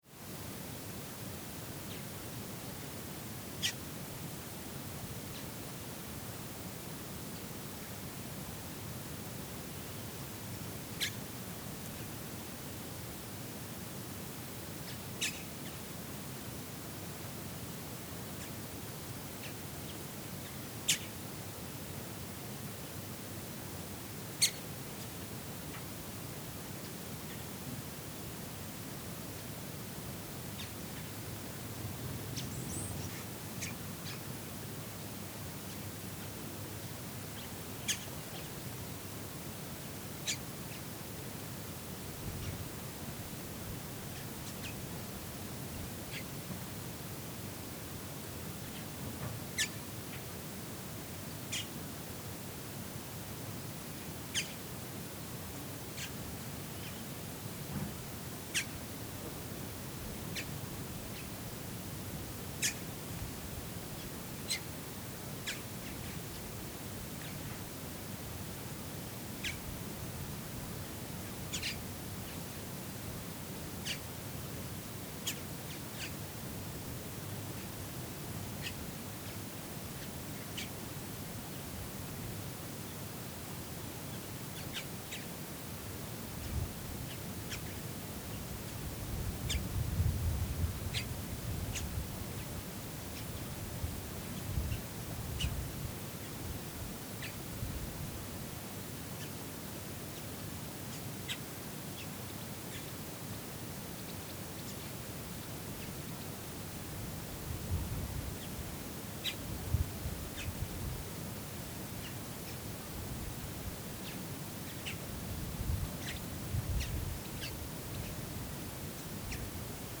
Soundscape: La Silla sunrise exterior
ss-ls-sunrise_stereo.mp3